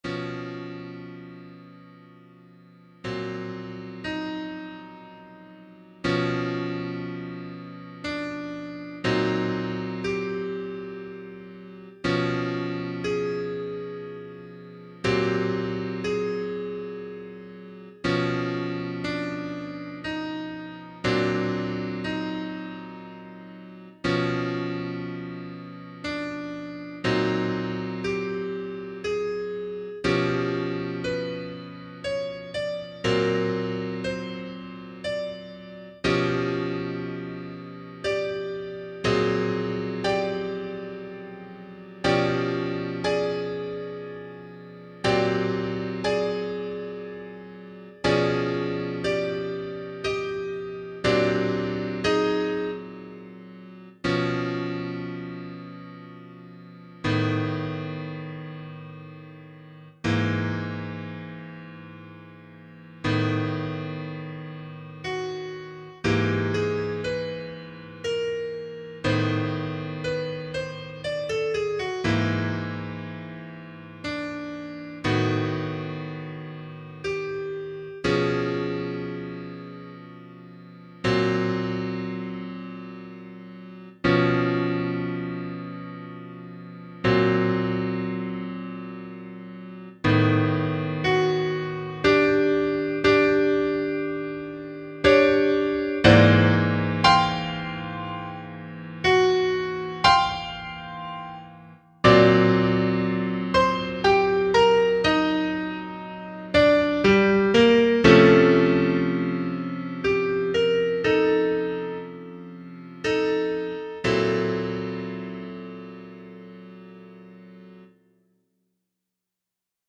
Preludes (I & II) - Piano Music, Solo Keyboard
The first one plays around with a serial matrix -purposefully breaking the means at which the matrix is used to generate the material for a piece.